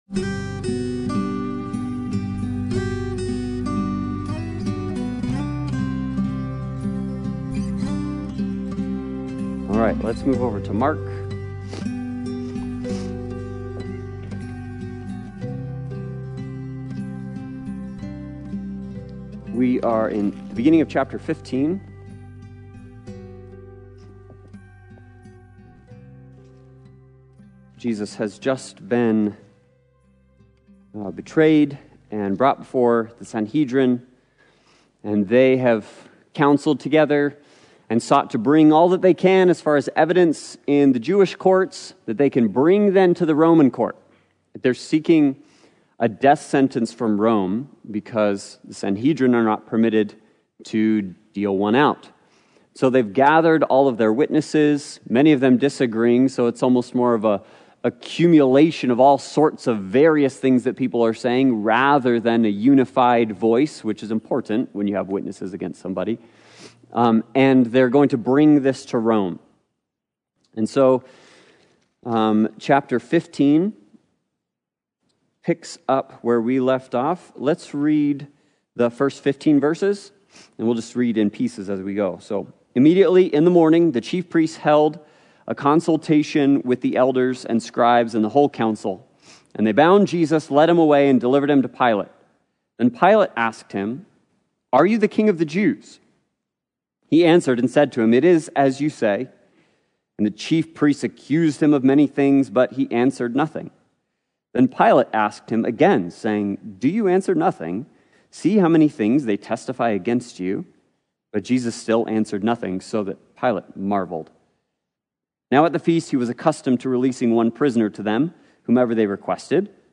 Sunday Bible Study